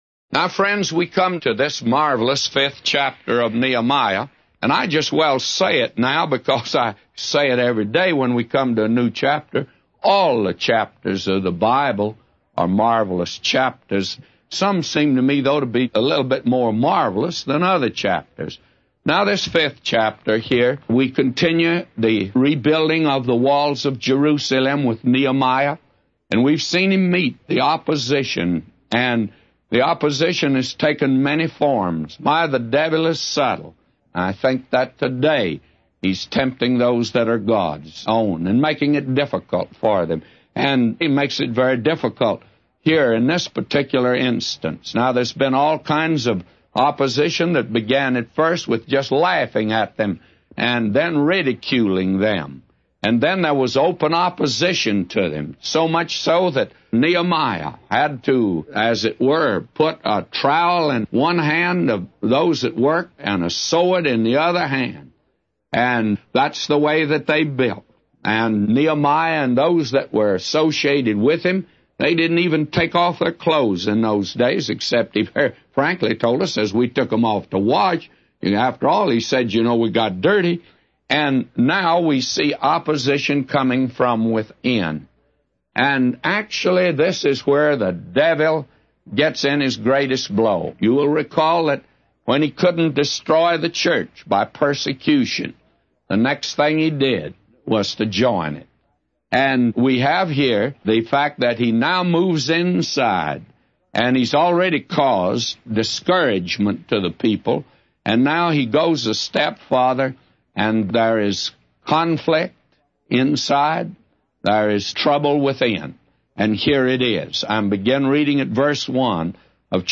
A Commentary By J Vernon MCgee For Nehemiah 5:1-999